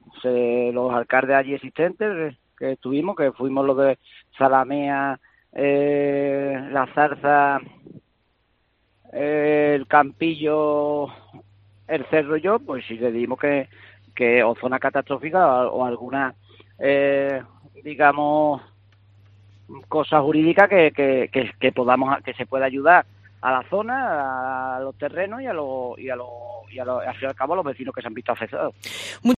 Jacinto Vázquez, alcalde de Almonaster